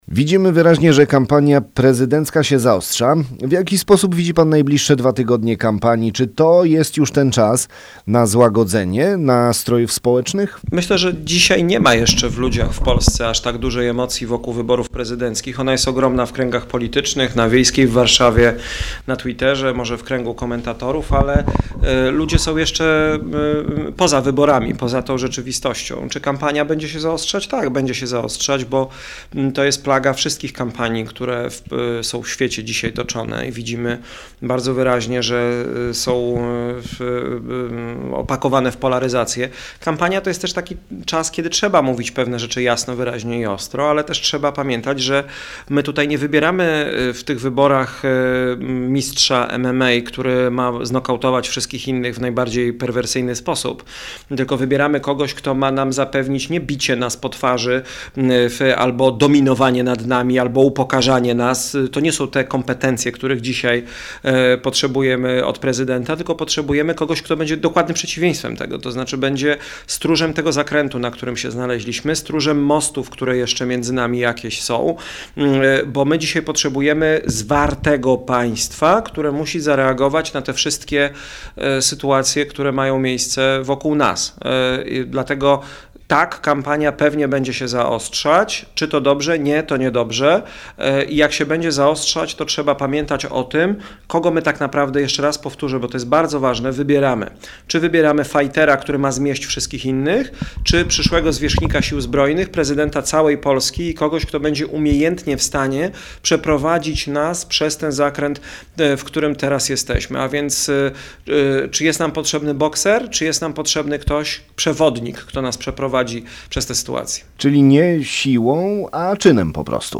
Rozmowa z Szymonem Hołownią, kandydatem na prezydenta RP.
Na spotkaniu z mieszkańcami prezentował swój program wyborczy i odpowiadał na pytania koszalinian. Udzielił też wywiadu dla Twojego Radia.